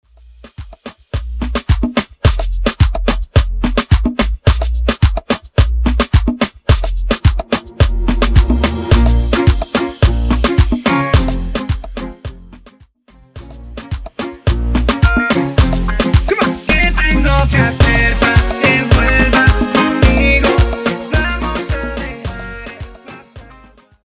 Intro Dirty